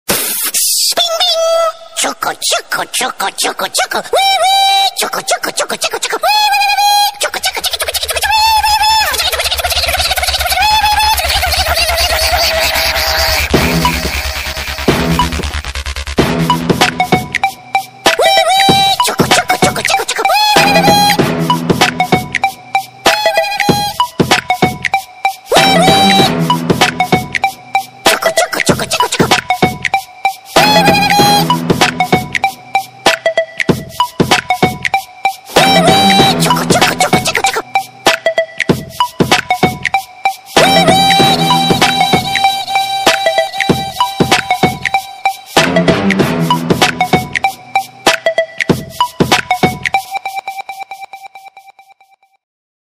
смешные